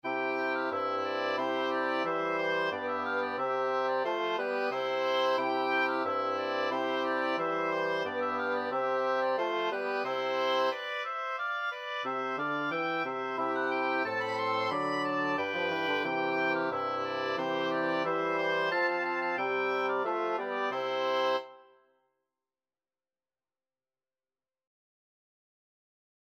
Free Sheet music for Wind Quartet
Oboe 1Oboe 2Bassoon 1Bassoon 2
"Deck the Halls" is a traditional Christmas, yuletide, and New Years' carol.
4/4 (View more 4/4 Music)
C major (Sounding Pitch) (View more C major Music for Wind Quartet )
Traditional (View more Traditional Wind Quartet Music)